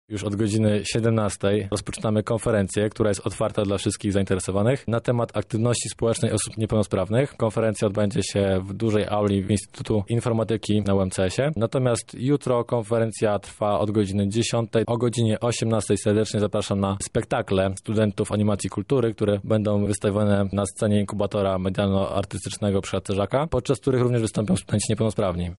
– tłumaczy